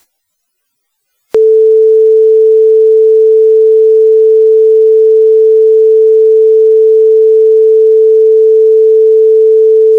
Using Gnome Wave Cleaner I was able to reproduce a similar kind of “beat” effect that we see in Audacity NR.
The settings to produce this sample were using a Blackman windowing function and “Power Spectral Subtraction” as the Noise suppression method.